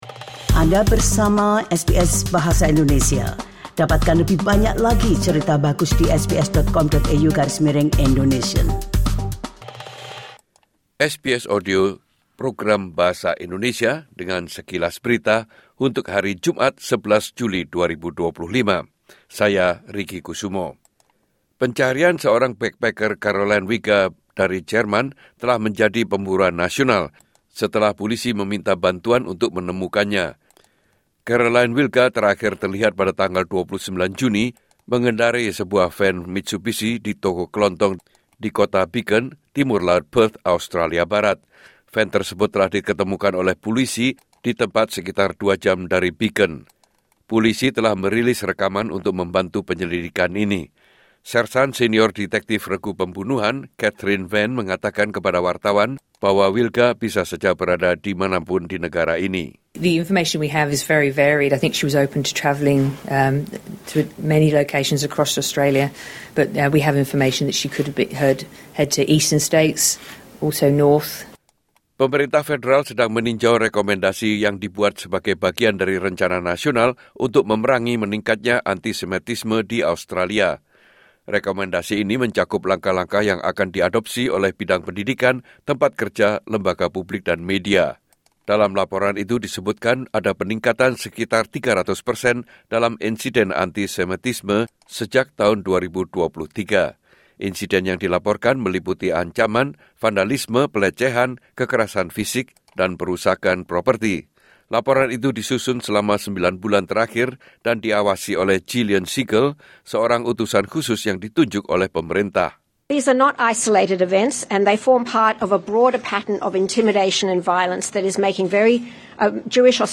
Berita Terkini SBS Audio Program Bahasa Indonesia - Jumat 11 Juli 2025